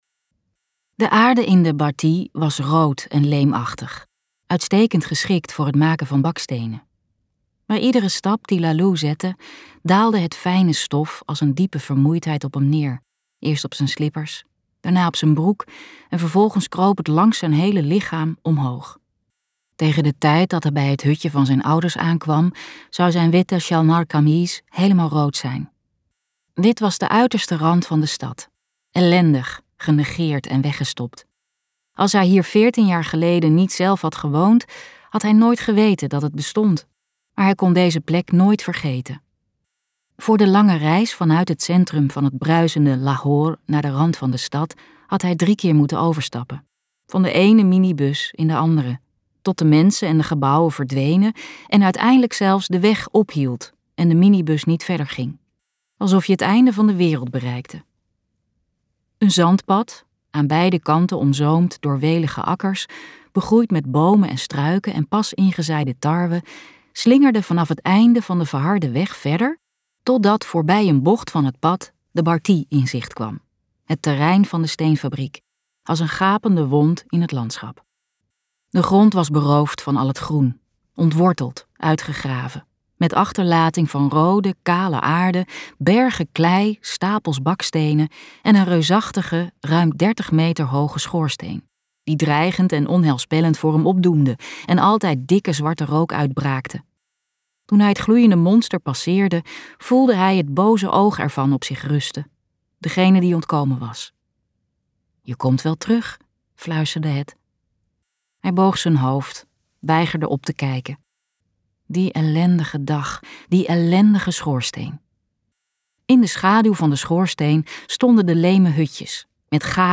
KokBoekencentrum | De jongen die de vuurvliegjes volgde luisterboek